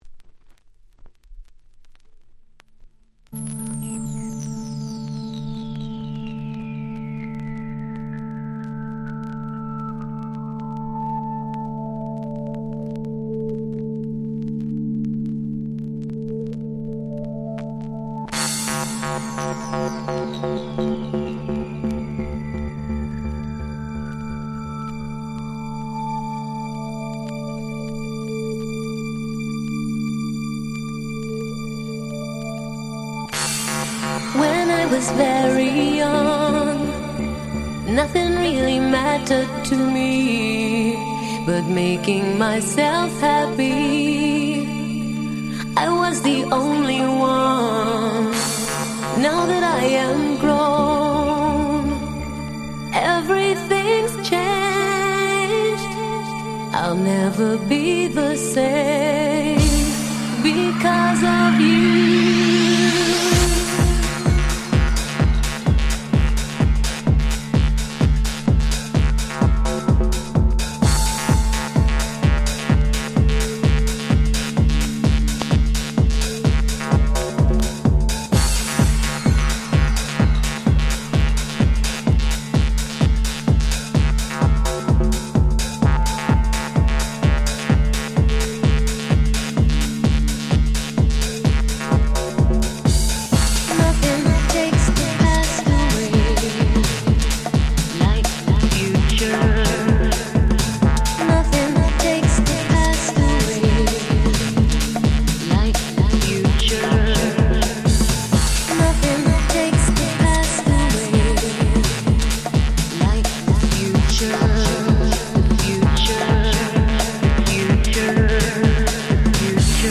99' Very Nice Vocal House / R&B !!